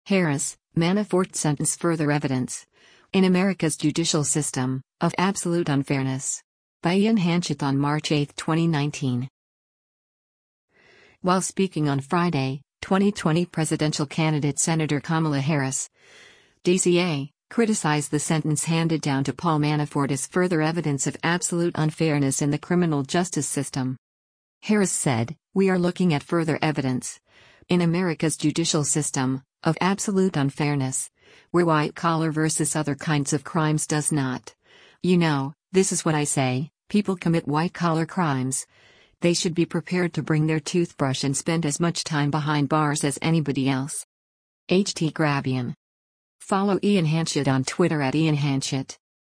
While speaking on Friday, 2020 presidential candidate Senator Kamala Harris (D-CA) criticized the sentence handed down to Paul Manafort as “further evidence” “of absolute unfairness” in the criminal justice system.